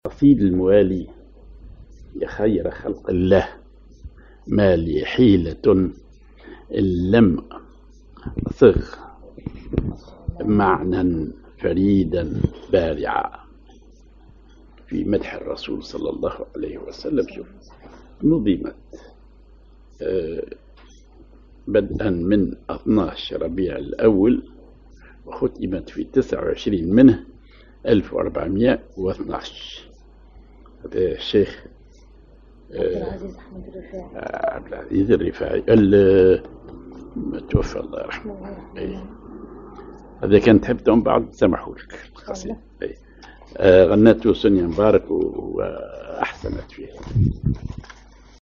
genre نشيد